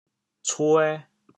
潮阳拼音“cuê2”的详细信息
cuê2.mp3